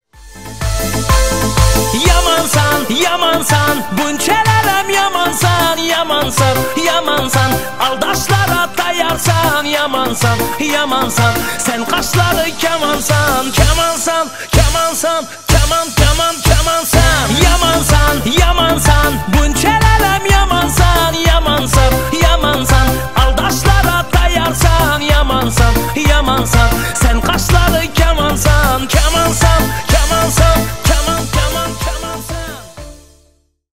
• Качество: 320 kbps, Stereo
Узбекские